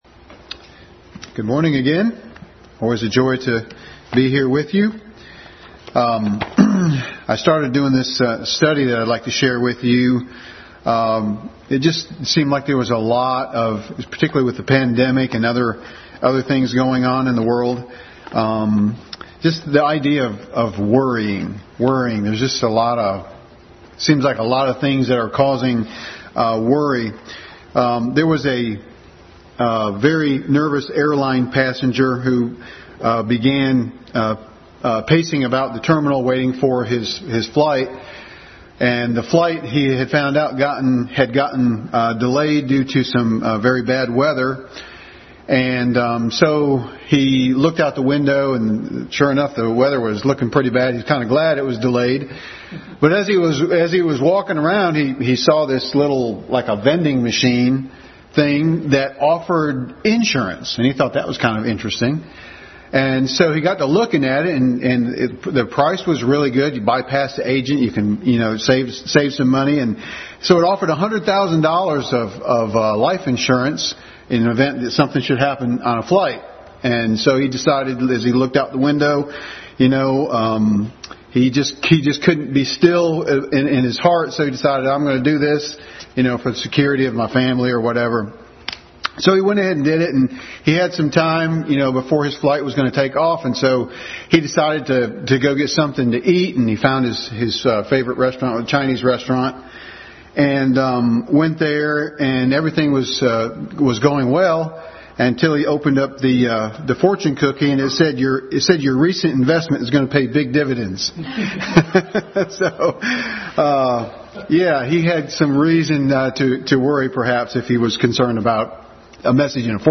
Adult Sunday School Class message.